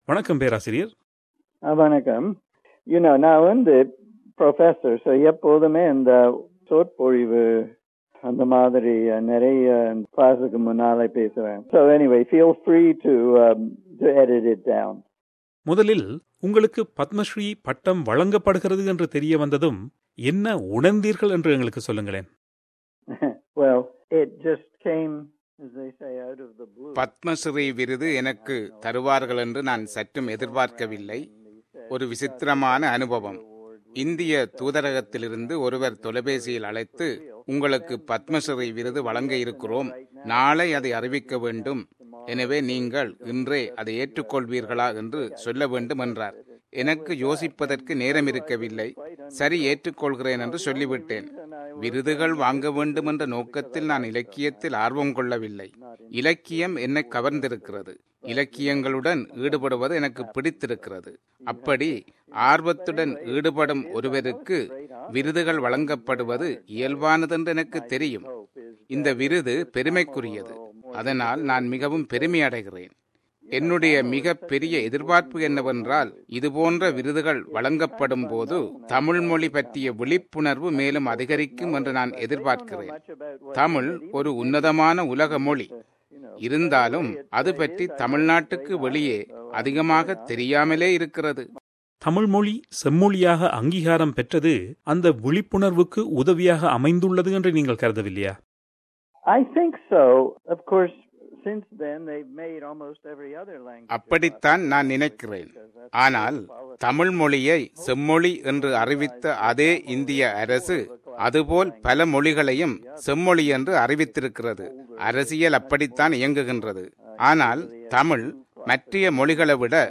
பேராசிரியர் ஜார்ஜ் ஹார்ட் அவர்களுக்கு பத்மஸ்ரீ விருது கொடுத்திருப்பது அந்த விருதுக்கே பெருமை சேர்ப்பது ஆகும். பேராசிரியர் ஹார்ட் அவர்களை நேர்கண்டு